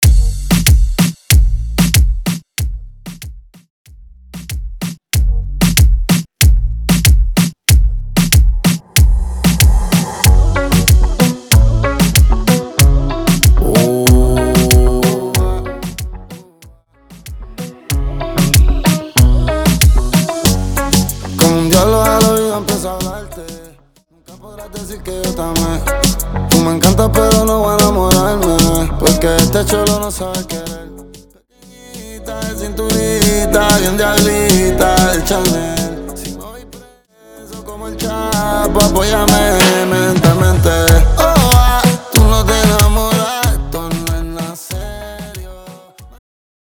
Intro Dirty